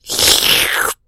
slurpie.mp3